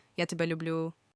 ya TEH-beh lyoo-BLYOO General love (family, friends, things)
"Люблю" sounds like "lyoo-BLYOO."